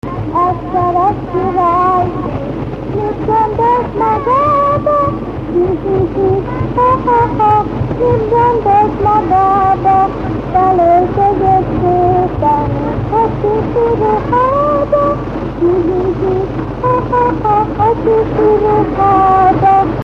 Alföld - Jász-Nagykun-Szolnok vm. - Jászberény
ének
Stílus: 8. Újszerű kisambitusú dallamok
Kadencia: V (5) X 1